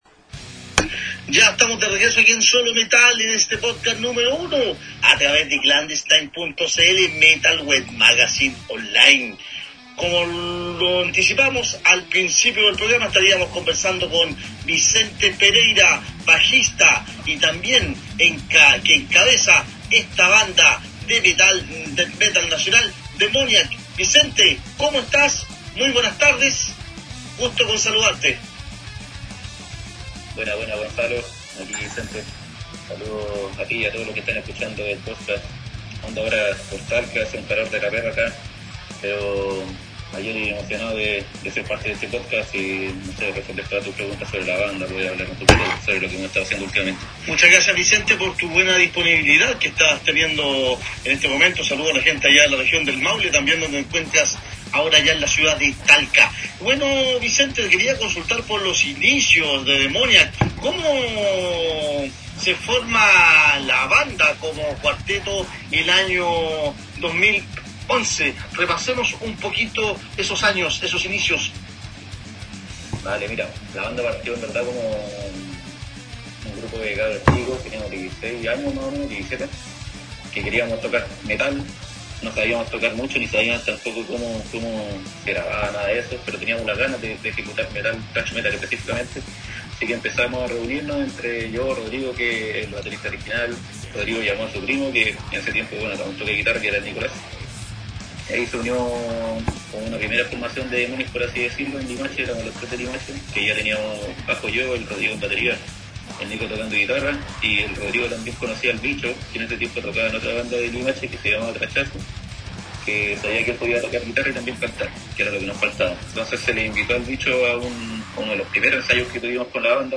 demoniac-entrevista-.mp3